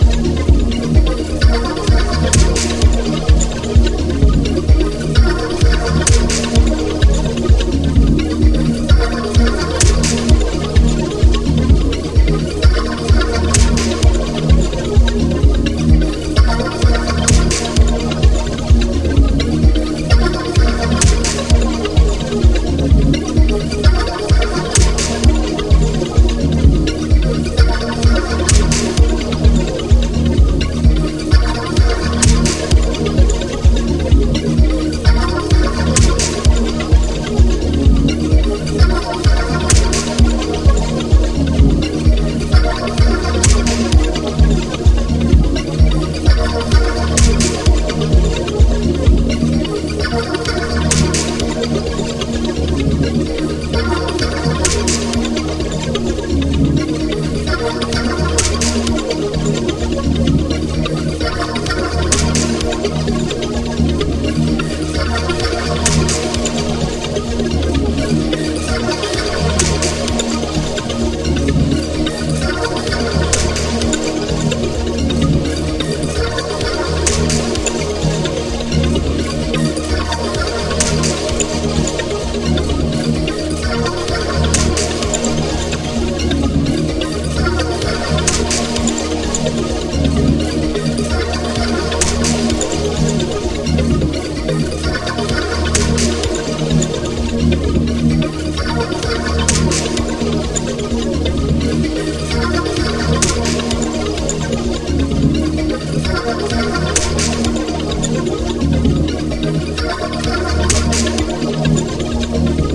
独創的な凹凸、奥深いイーヴンキックの世界。
House / Techno